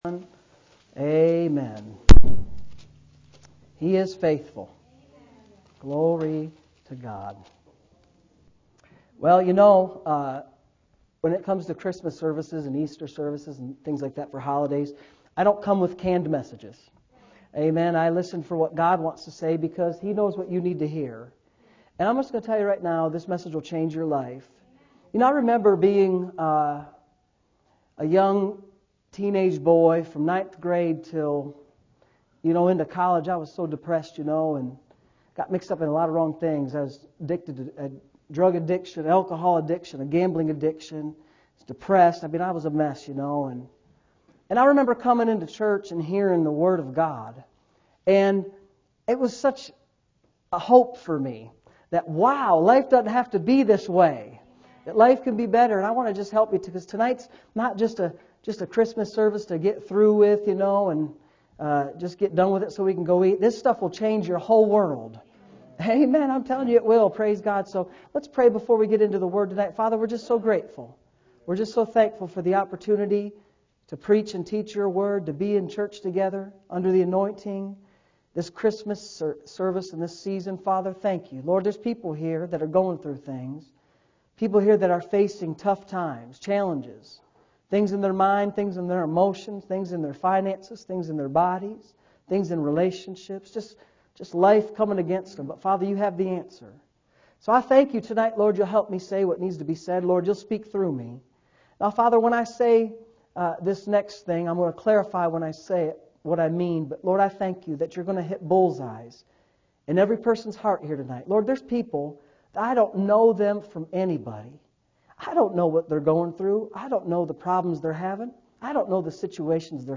Saturday Evening Services